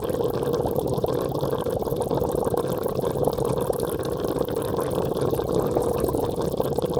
water_bubbling_01_loop_short.wav